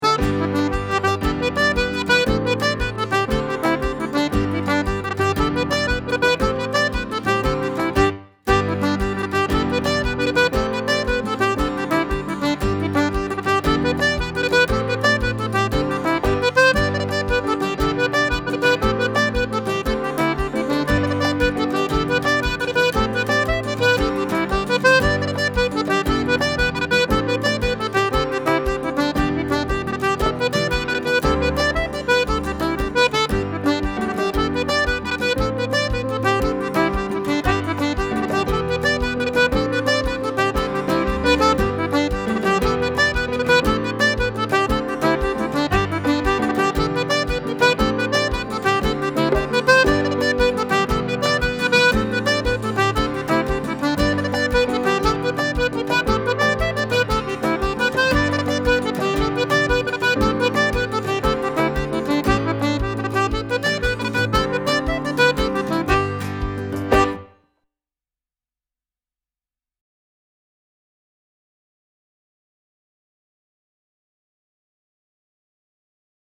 Light Jigs